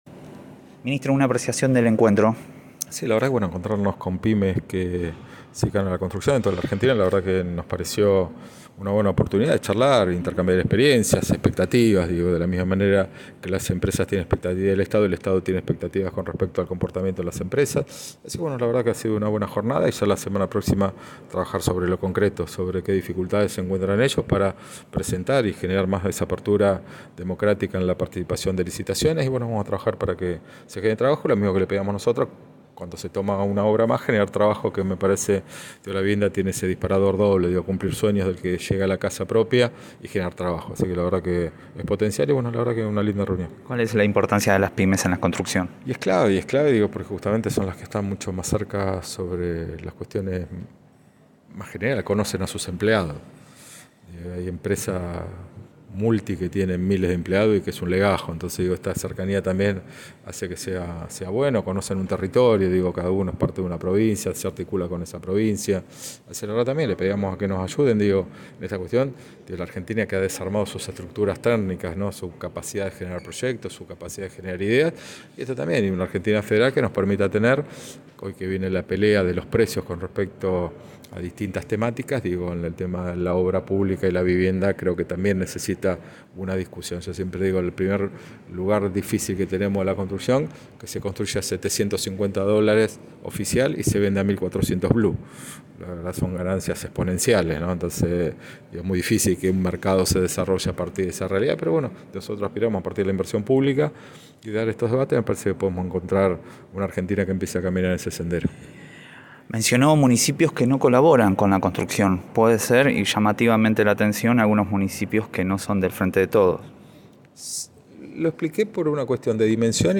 Luego de su participación en la Asamblea Anual de la Confederación de Pymes Constructoras, el ministro de Desarrollo Territorial y Hábitat de la Nación, Jorge Ferraresi aseguró en Radio Estación Sur que fue una buena oportunidad para compartir experiencias y expectativas y que como las empresas tienen expectativas del Estado, el Estado también tiene expectativas con el comportamiento de las empresas.